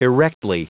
Prononciation du mot erectly en anglais (fichier audio)
Prononciation du mot : erectly